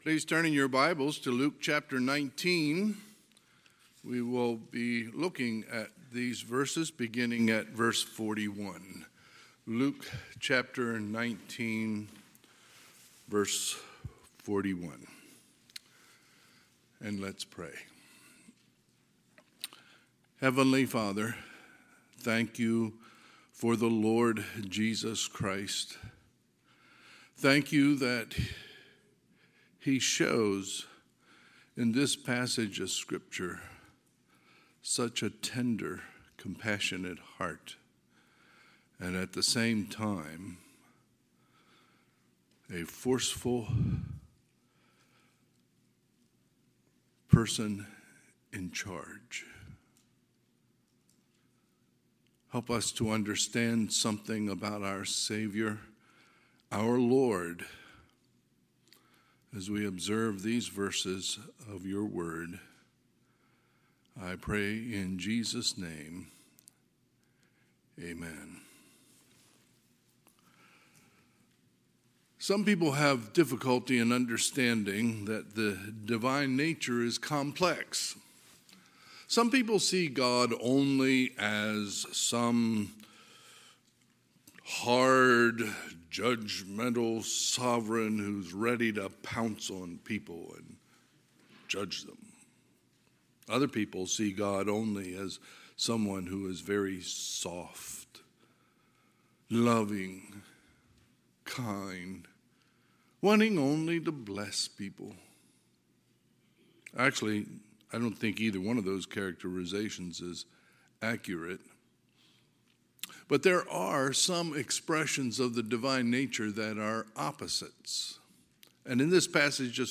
Sunday, March 12, 2023 – Sunday AM
Sermons